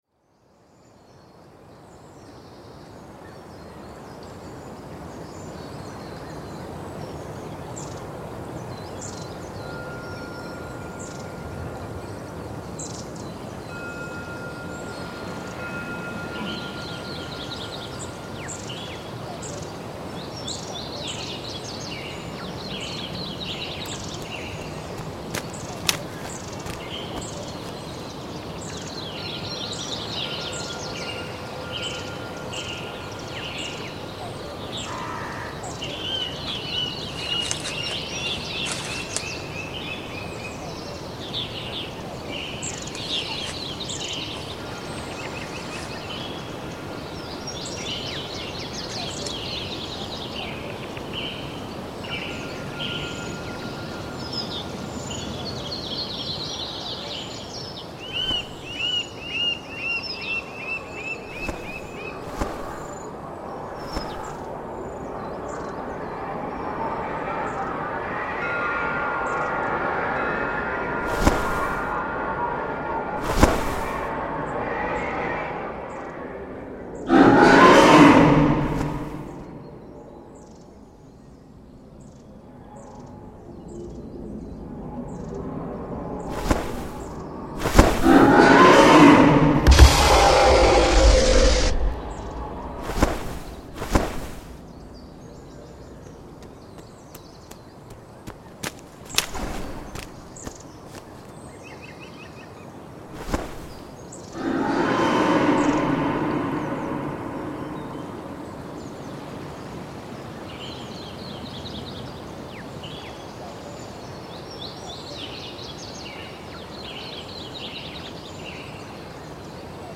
Dragón medieval
Uno de sus aprendices gracias a los artilugios alquímicos de la epoca logró captar el sonido de uno de los dragones con los que estaban trabajando para su domesticación.
Pieza realizada para Radiombligo y presentada el 7 de octubre de 2008 en su programa matutino.
Octubre de 2008 Equipo: Edición en computadora